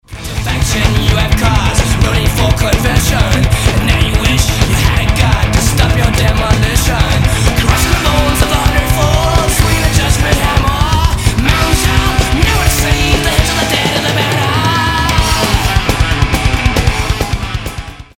speed metal
The song builds:
And builds:
While it might have a thin production and whiney vocals